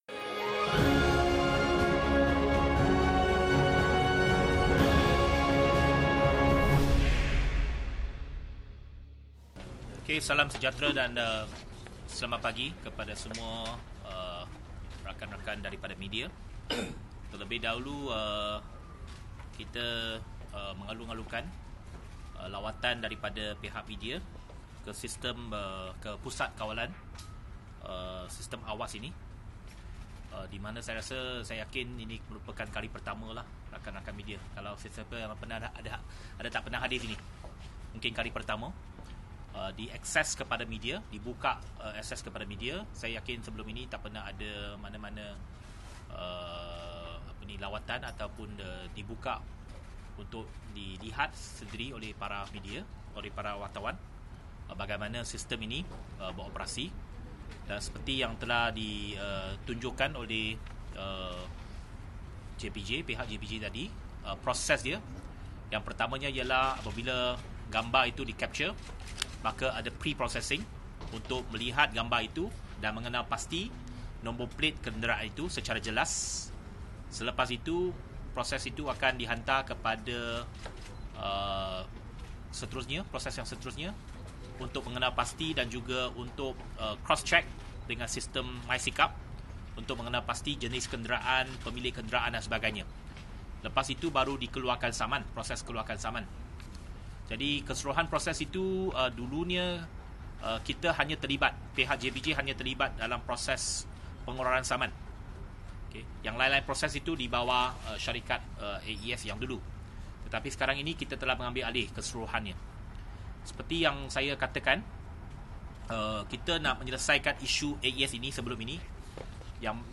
Berikut merupakan sidang media Menteri Pengangkutan Anthony Loke selepas lawatan ke Pejabat Kawalan AwAS di Kelana Jaya.